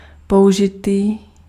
Ääntäminen
Synonyymit opportunité cas Ääntäminen France: IPA: [ɔ.ka.zjɔ̃] Haettu sana löytyi näillä lähdekielillä: ranska Käännös Ääninäyte Substantiivit 1. příležitost {f} Muut/tuntemattomat 2. použitý Suku: f .